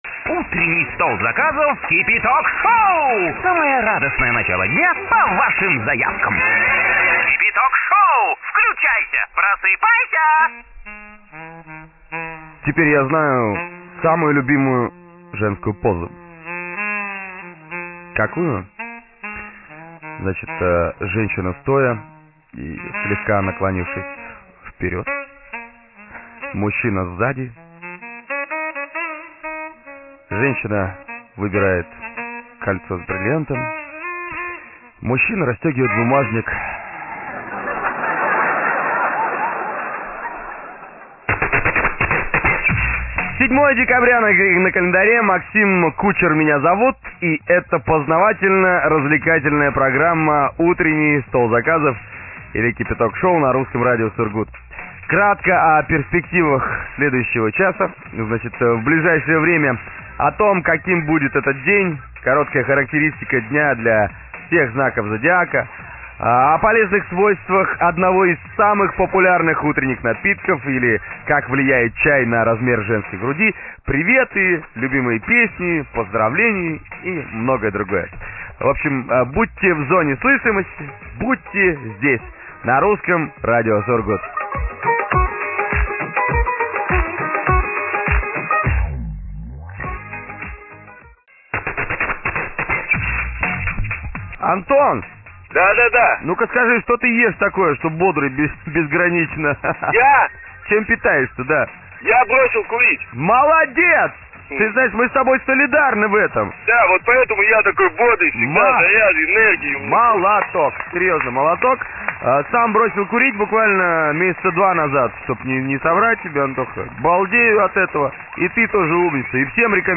Шоу, которое выходит на "Русском Радио" (Сургут) во время программы по заявкам.
запись эфира